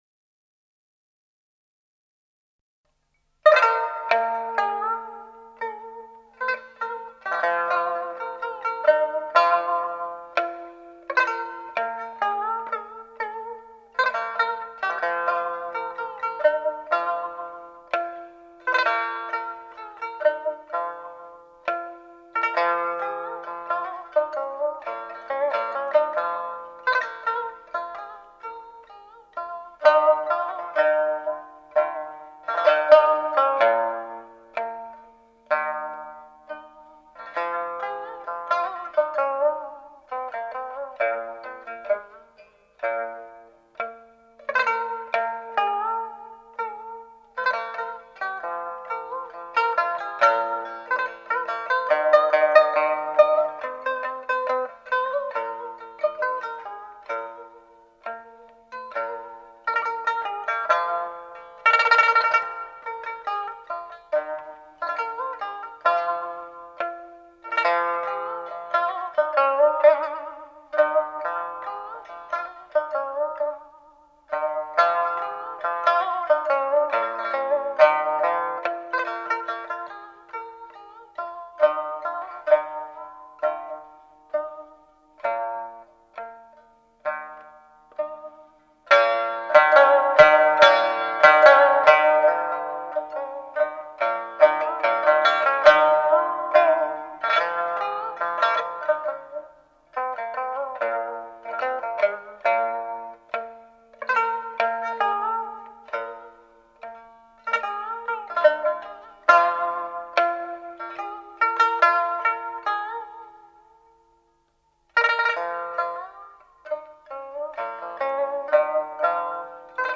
琵琶